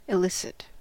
Ääntäminen
IPA: /hɛʁˈfoːˌʁuːfən/